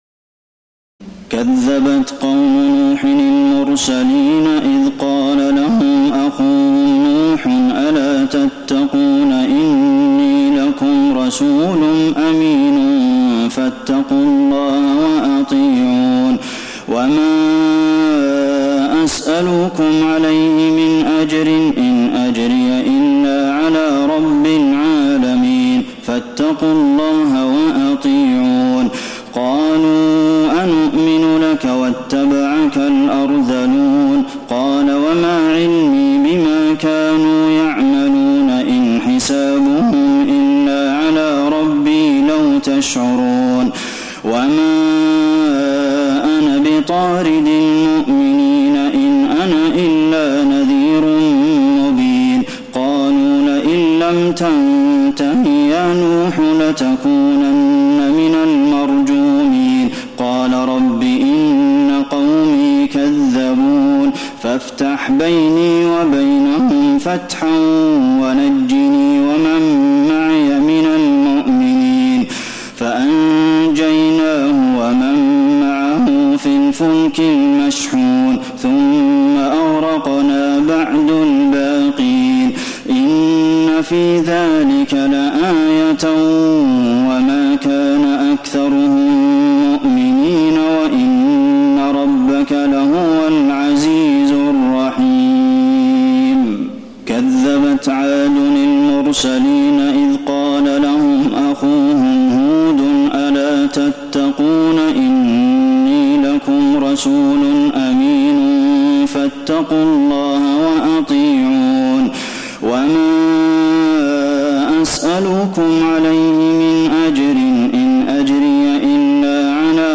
تراويح الليلة الثامنة عشر رمضان 1432هـ من سورتي الشعراء (105-227) والنمل (1-53) Taraweeh 18 st night Ramadan 1432H from Surah Ash-Shu'araa and An-Naml > تراويح الحرم النبوي عام 1432 🕌 > التراويح - تلاوات الحرمين